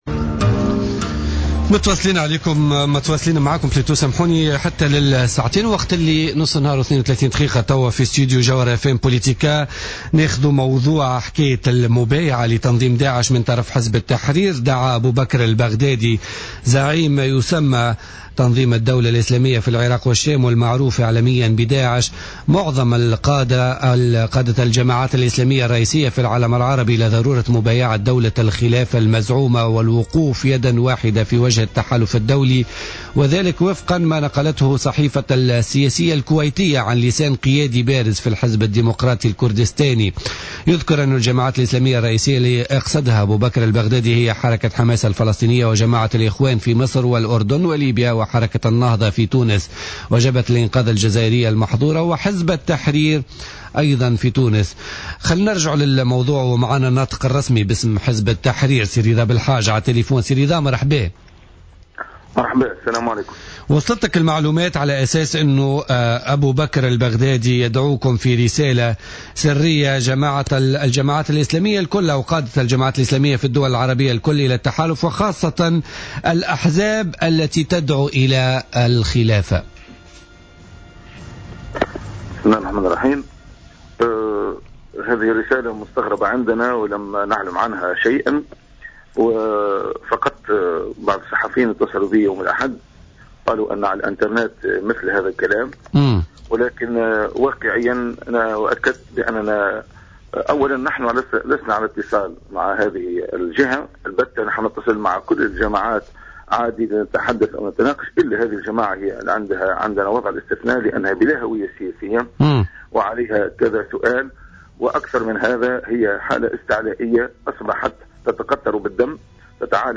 في مداخلة له في بوليتيكا